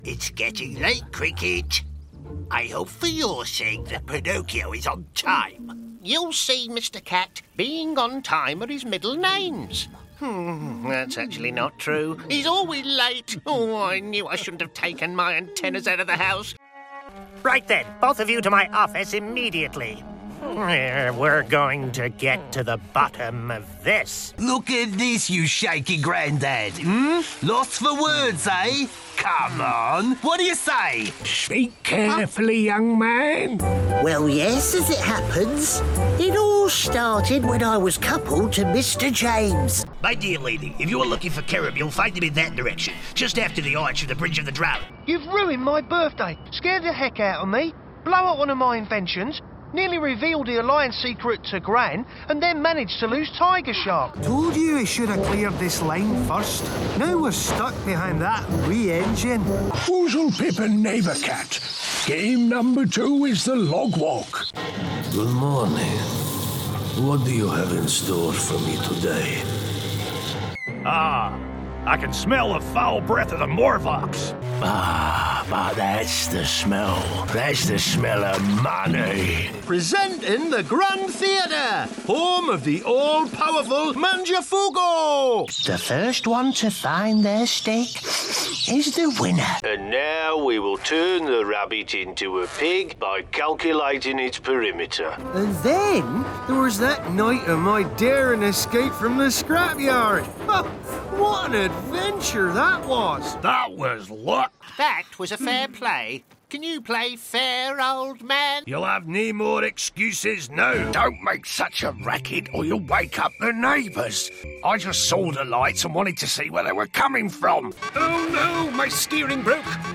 Verastile actor with great range of dialects and characters
Animation voices